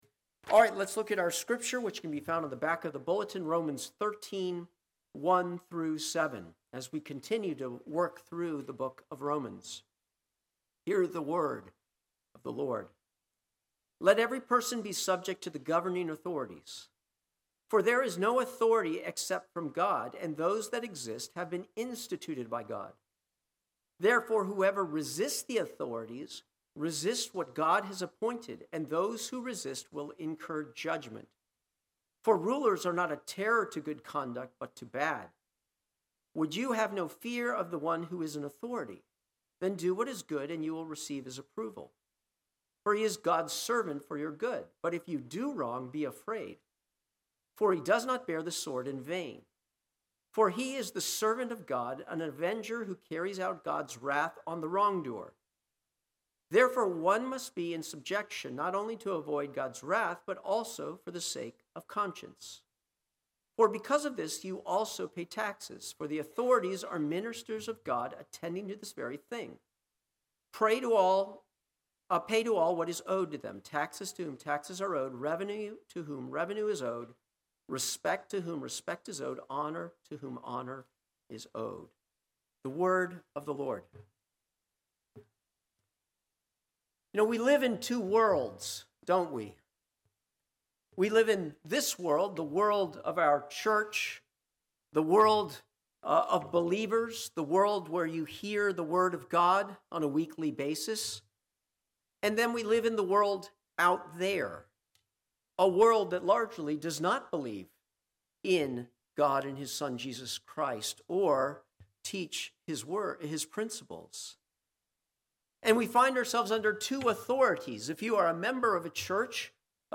Sermons
Sermons from Redeemer Presbyterian Church in Virginia Beach, VA.